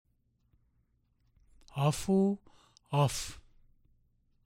1. Vowel contrasts
A. Listen to the difference between a and in the pairs of words below.
(Each example is given in both short and long forms.)